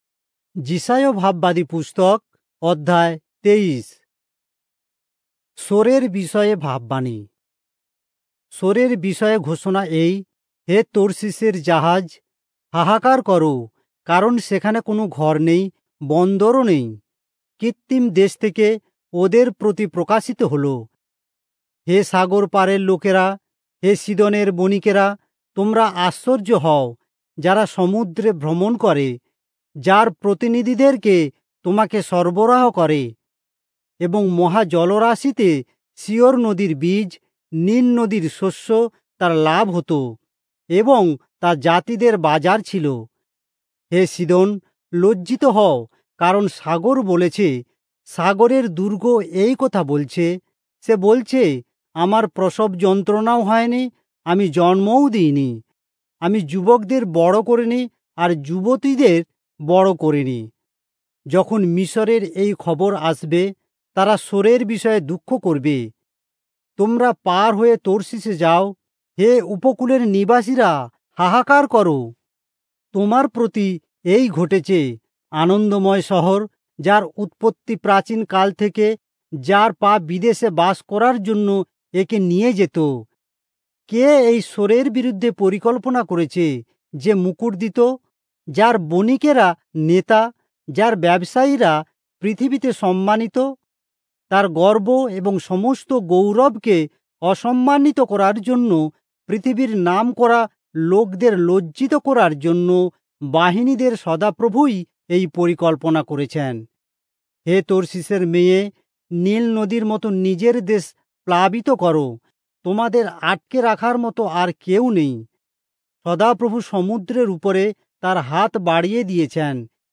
Bengali Audio Bible - Isaiah 18 in Irvbn bible version